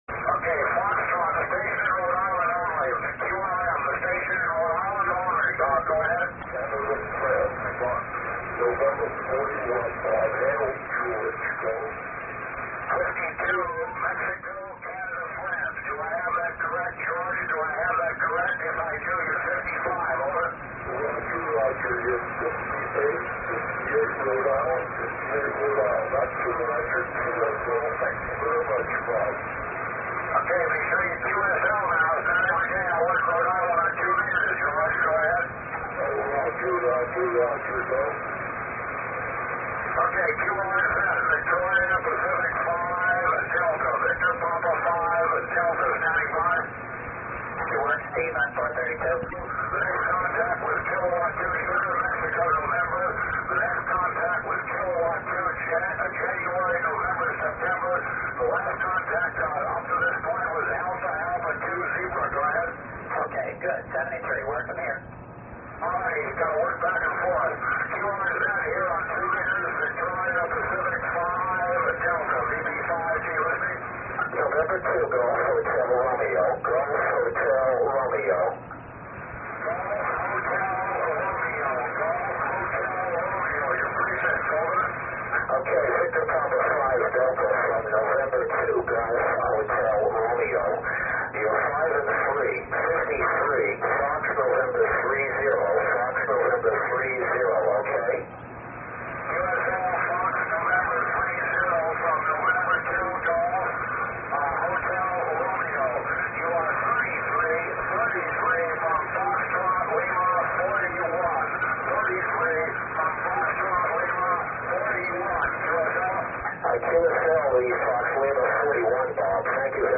You'll hear several familiar callsigns working him.
Audio originally recorded on cassette tape